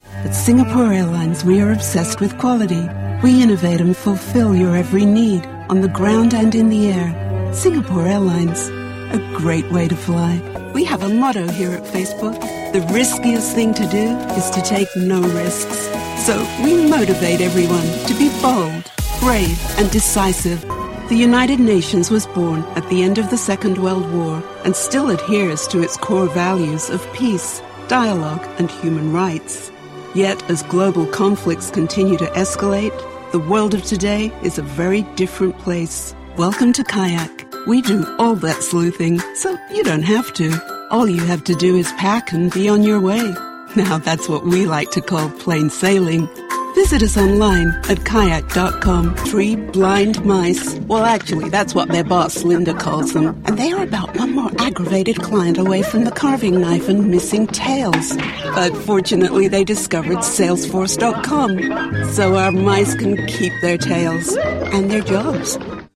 I keep my delivery honest, believable, and authentic, and can also be humorous with a touch of irony and pizzazz when necessary.
TLM103 mic
ContraltoProfundoBajo
ConversacionalCálidoAmistosoGenuinoSofisticadoConfiableEntusiastaConfiadoConfidencialElocuenteInnovadorSerioCosmopolitaArtísticaCalmanteCreíble